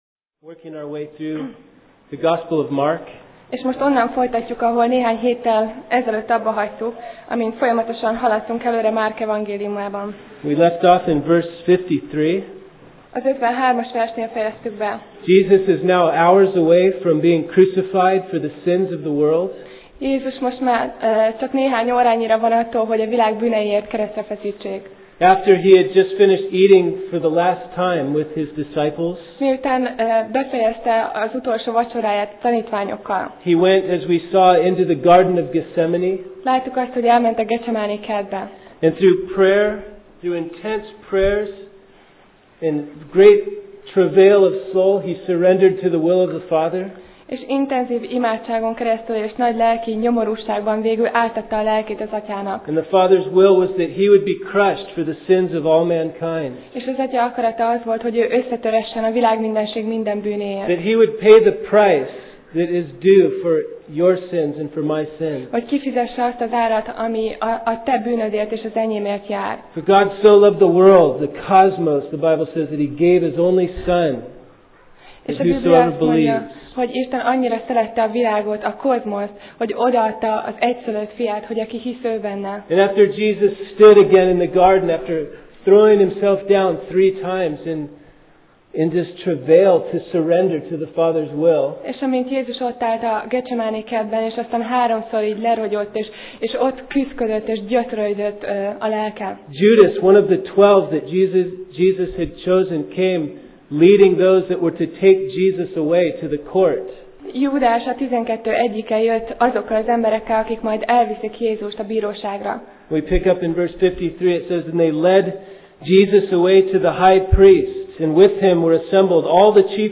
Márk Passage: Márk (Mark) 14:53-72 Alkalom: Vasárnap Reggel